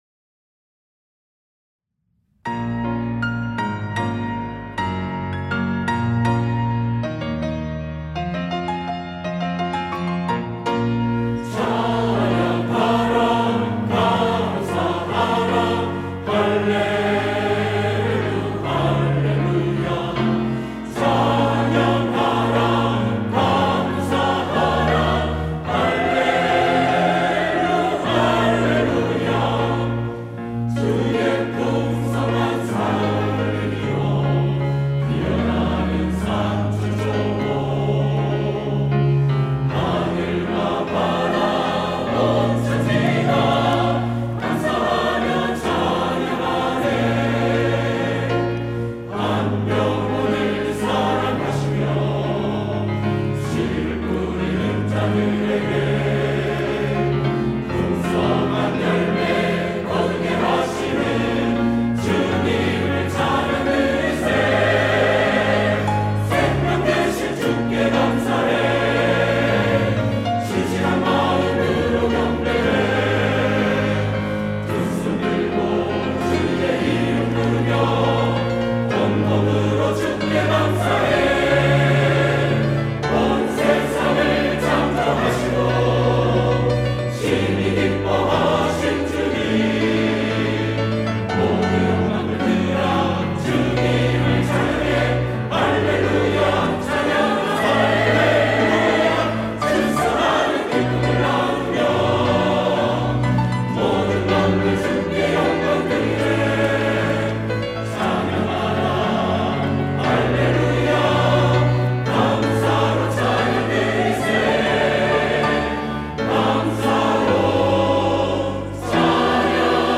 할렐루야(주일2부) - 감사로 찬양 드리세
찬양대